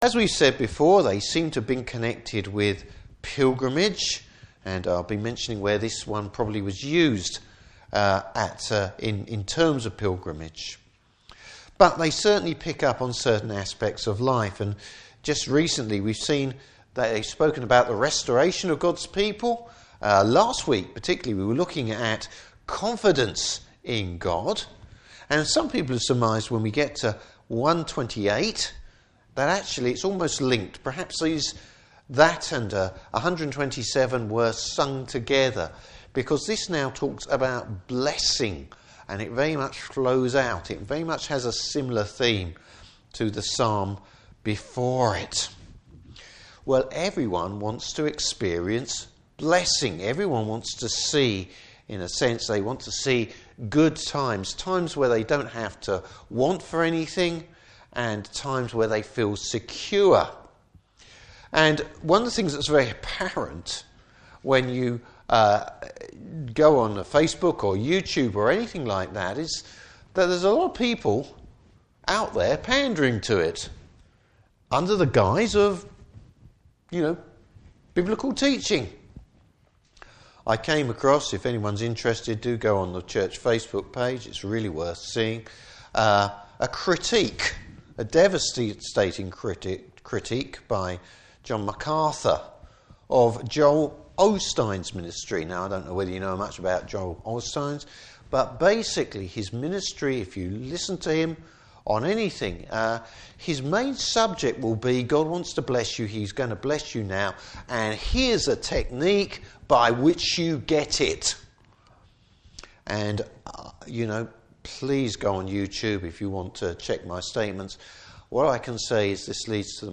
Service Type: Evening Service Bible Text: Psalm 128.